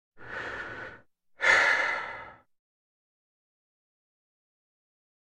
Звуки тяжелого вздоха
Звук усталости от всего этого